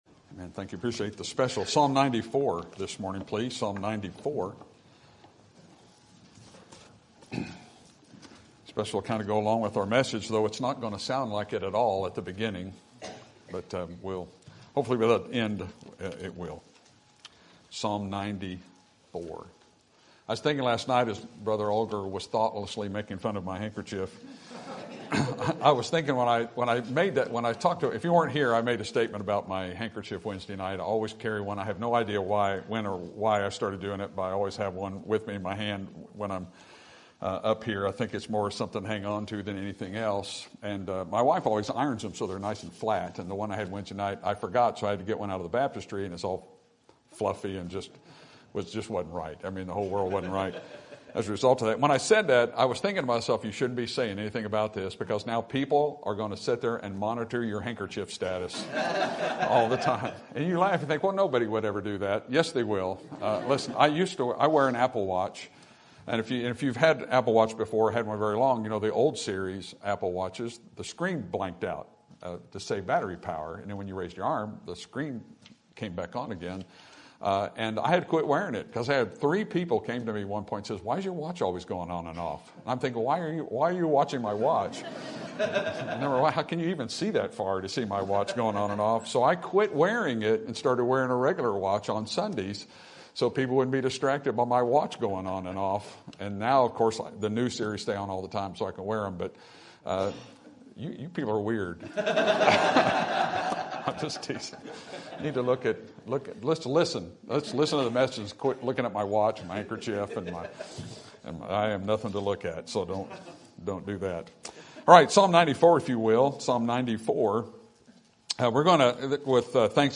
Sermon Topic: General Sermon Type: Service Sermon Audio: Sermon download: Download (24.3 MB) Sermon Tags: Psalm Thanksgiving God Life